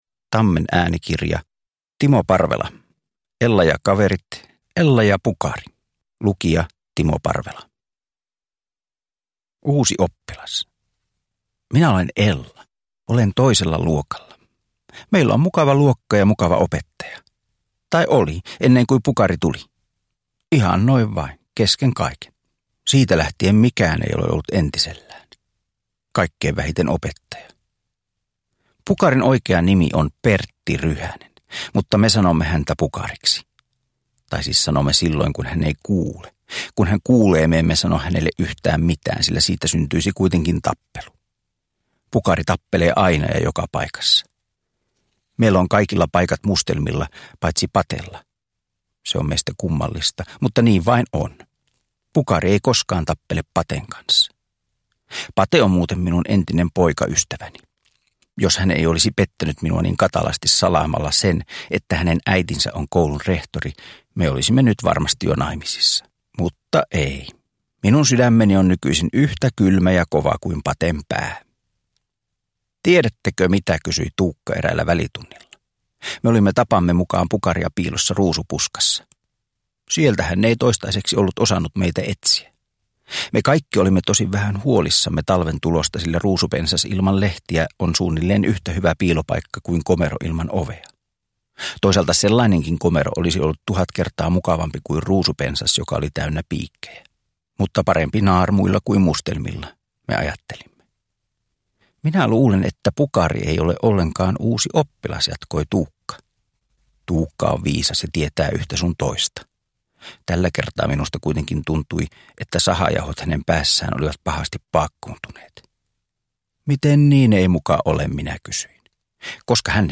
Ella ja Pukari – Ljudbok
Uppläsare: Timo Parvela